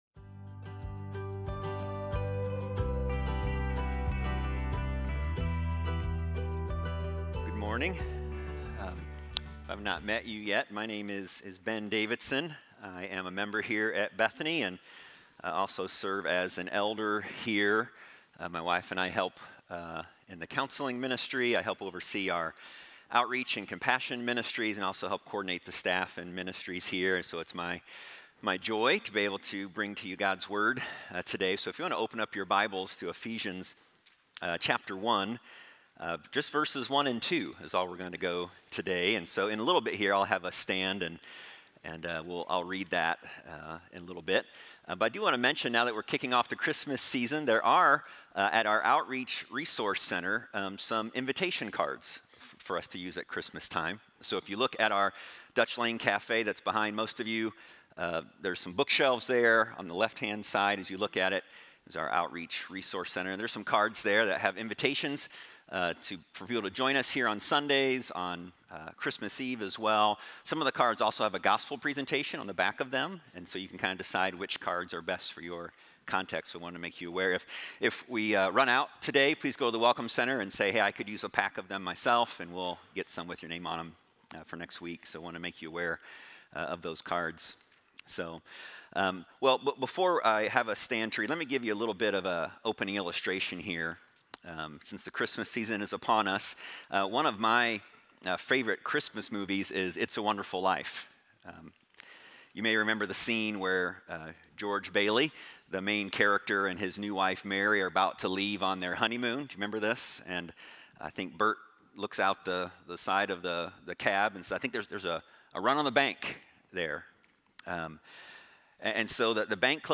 Sermon Series Archives - Listen to Sermons from Bethany Community Church in Washington, IL